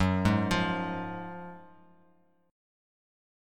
F#M7sus2 chord